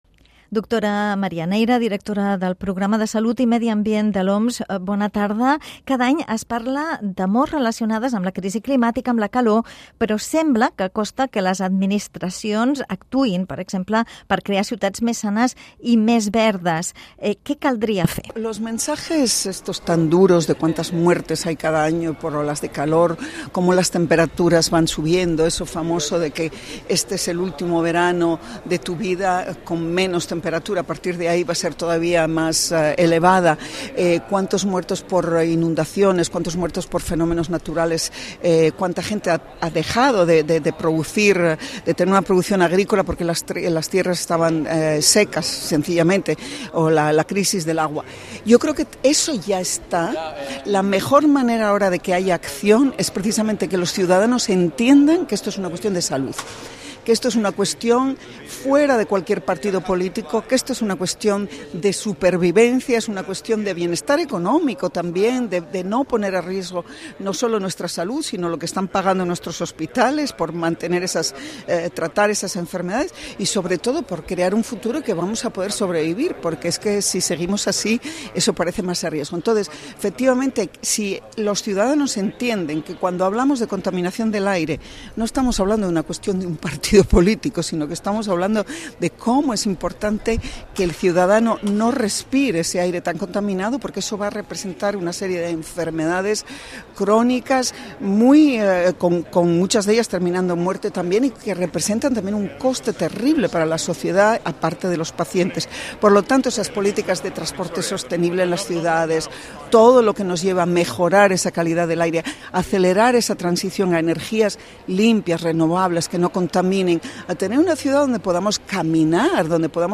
La responsable del programa de salut i medi ambient de l'Organització Mundial de la Salut, María Neira, ha dit en una entrevista a 3CatInfo que les cimeres sobre el clima no han servit per avançar prou contra el canvi climàtic i que cal enfocar aquesta crisi des de la perspectiva de la salut pública.